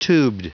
Prononciation du mot tubed en anglais (fichier audio)
Prononciation du mot : tubed